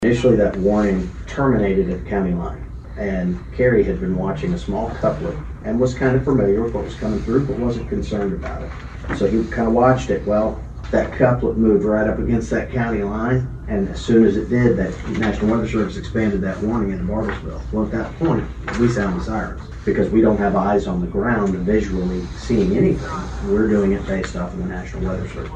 The Washington County Commissioners held a fairly standard meeting Monday approving various reports, but during the Commissioners comment portion of the meeting, the subject of tornado siren policy was discussed.
District 3 Commissioner Mike Dunlap asked District 1 Commissioner Mitch Antle to explain how the decision is made to sound sirens after Thursday's storm prompted a